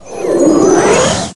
Magic4.ogg